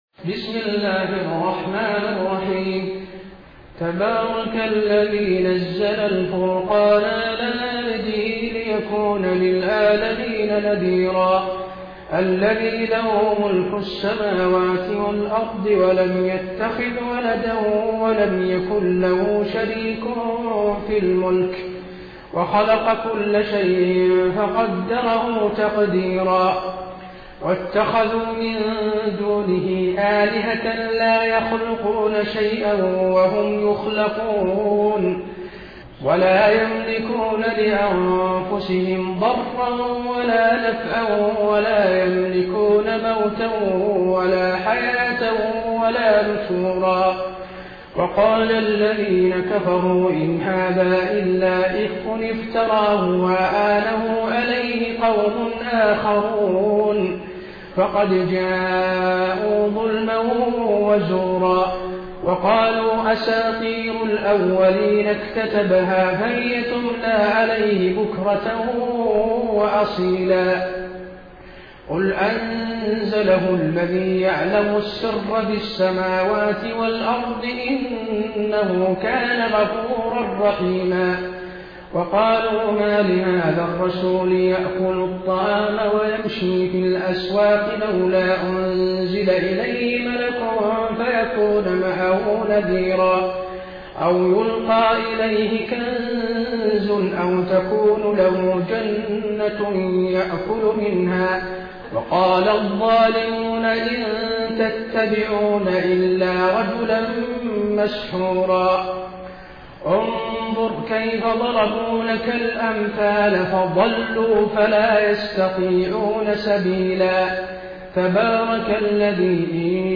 taraweeh-1433-madina